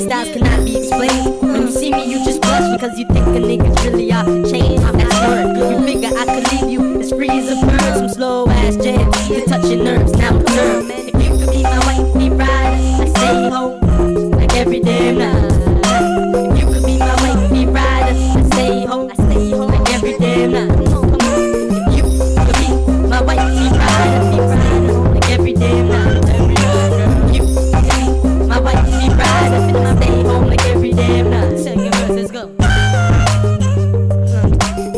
Жанр: Хип-Хоп / Рэп
Hip-Hop, Rap